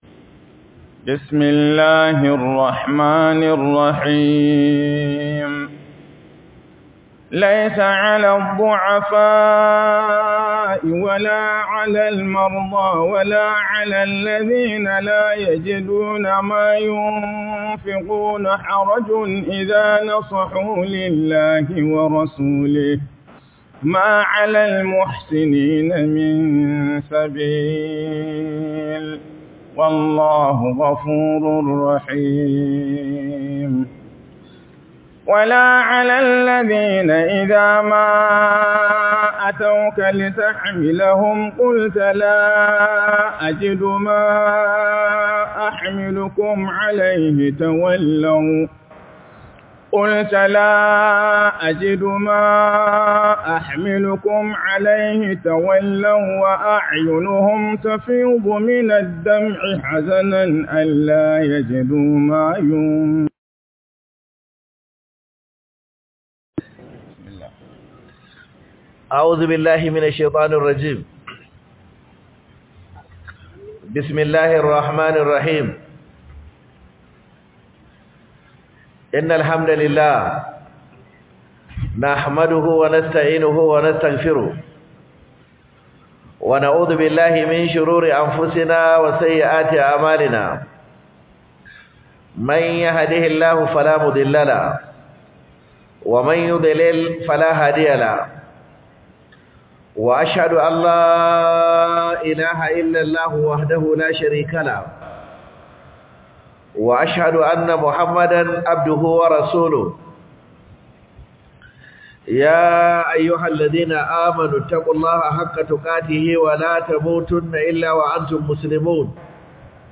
By Sheikh Kabiru Haruna Gombe Tafsir Duration: 35:26 4 downloads